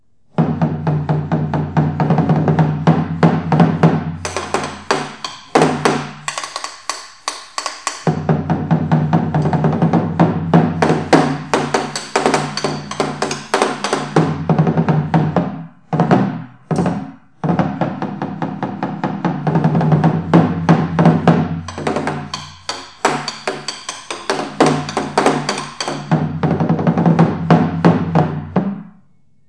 Trống Chiến
rống Chiến rộn ràng, khỏe, vang xa.